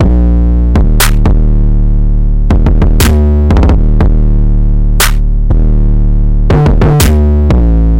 Tag: 120 bpm Trap Loops Drum Loops 1.35 MB wav Key : Unknown FL Studio